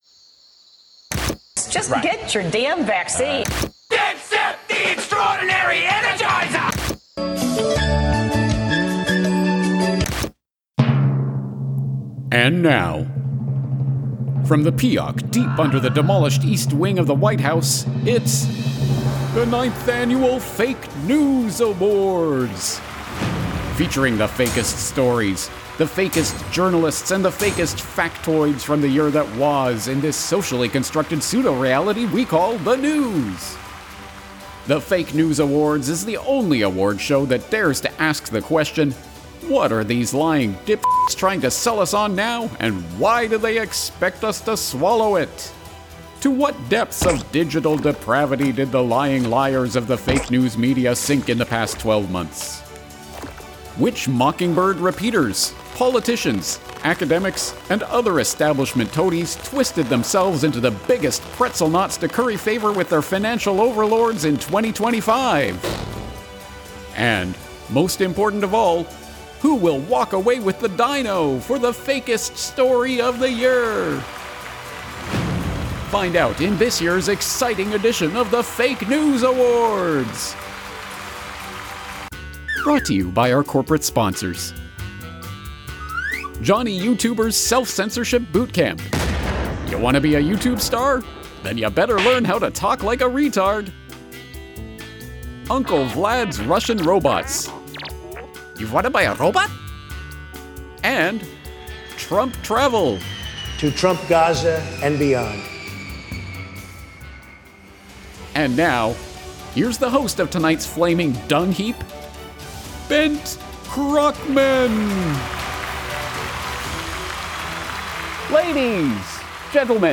WATCH ON: / / / / / or DOWNLOAD THE MP4 TRANSCRIPT VOICEOVER : And now…